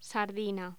Locución: Sardina
voz
Sonidos: Voz humana